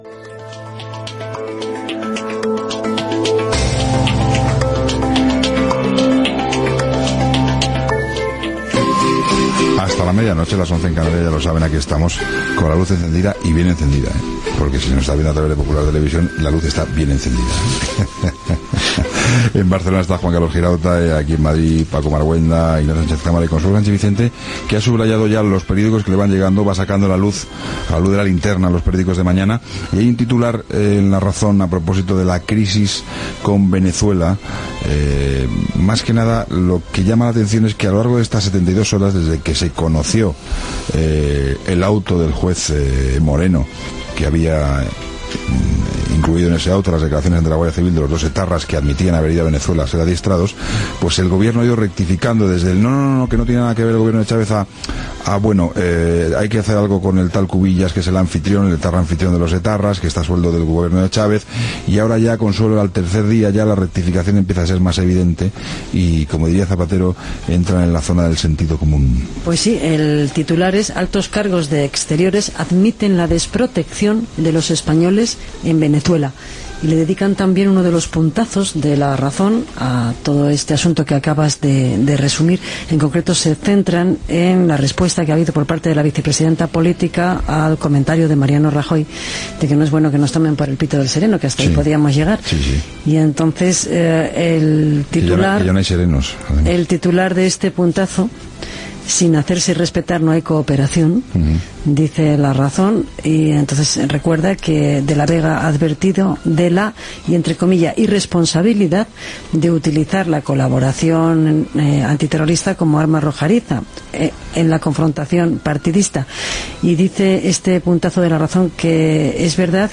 Tertulia comentant els membres d'ETA que viuen a Veveçuela, revista de premsa, opinions dels oïdors, avenç del programa "El partido de las 12", comiat Gènere radiofònic Informatiu